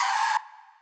pbs - crispy skrt [ Sfx ].wav